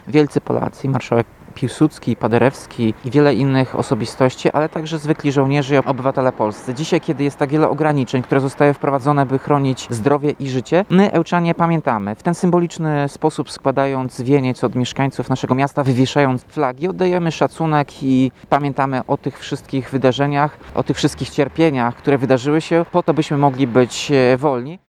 – Ważne, żeby pamiętać o tych wszystkich, którzy 102 lata temu wywalczyli nam niepodległość – mówi Tomasz Andrukiewicz, prezydent Ełku.